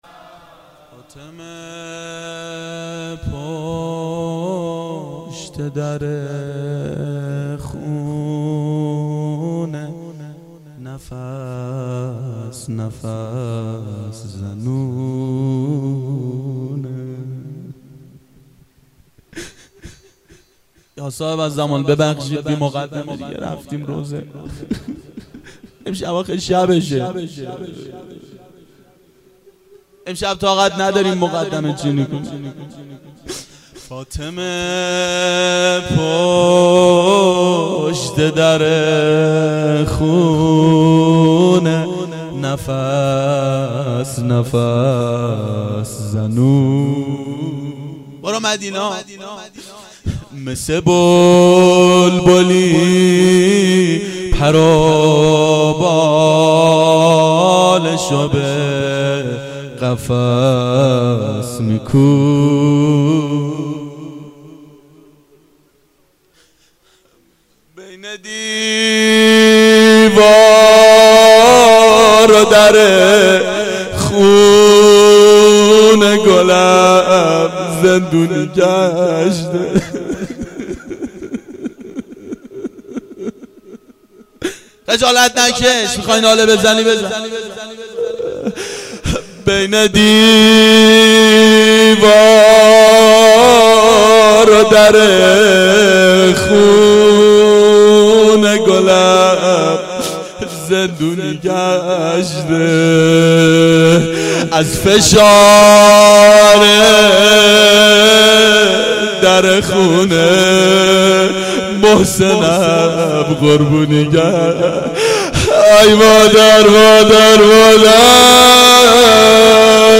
روضه حضرت زهرا سلام الله علیها
• شام غریبان حضرت زهرا سلام الله علیها 89 هیئت محبان امام حسین علیه السلام شهر اژیه
02-روضه-حضرت-زهرا-س.mp3